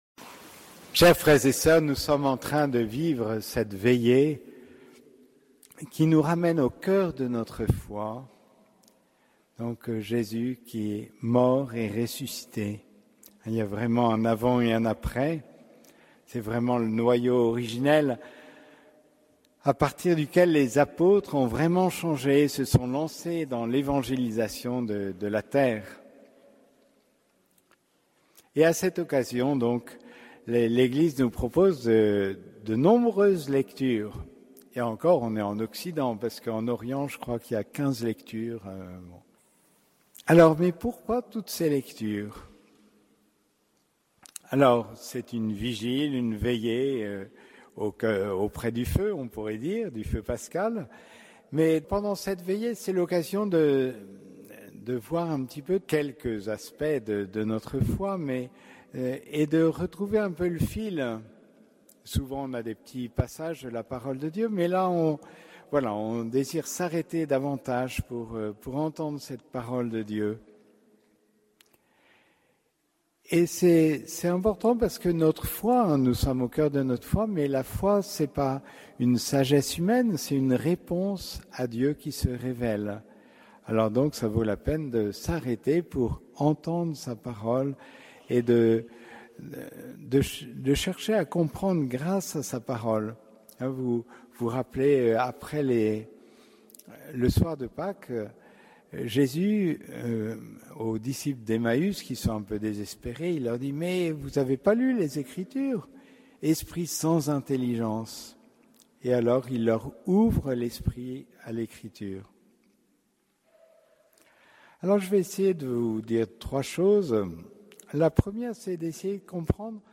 Homélie du Samedi Saint - Veillée pascale